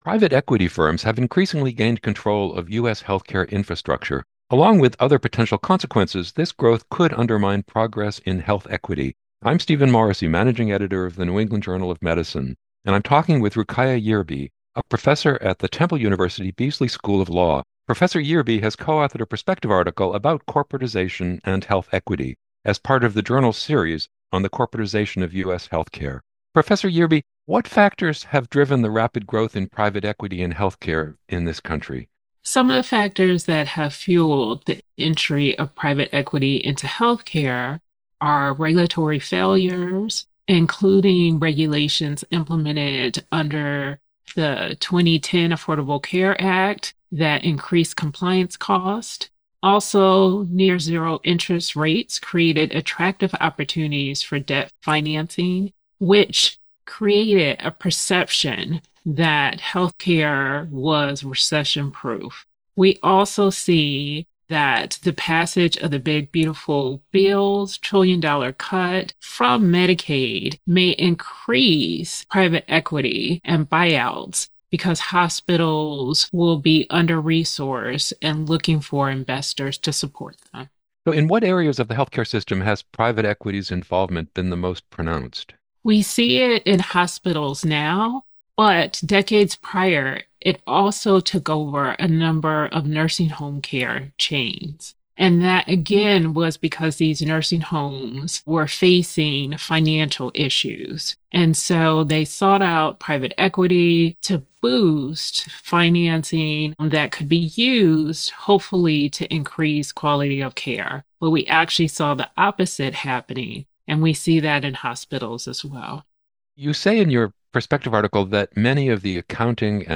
NEJM Interview